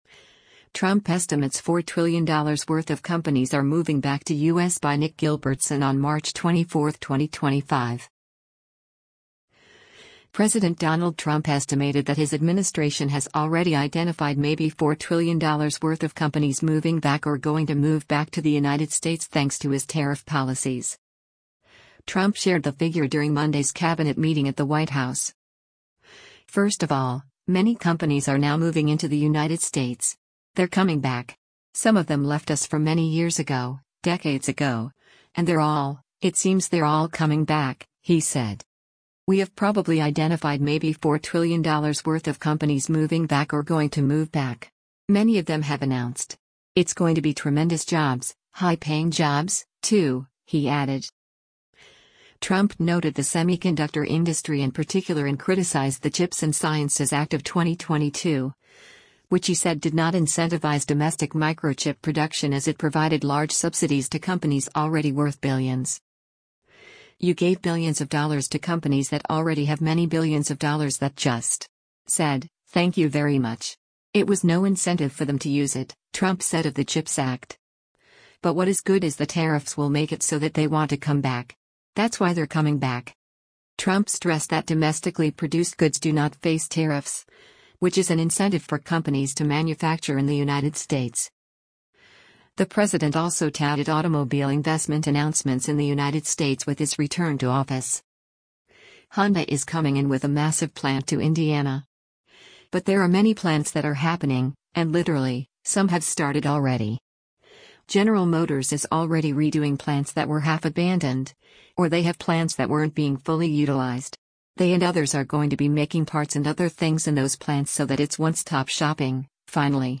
Trump shared the figure during Monday’s Cabinet meeting at the White House.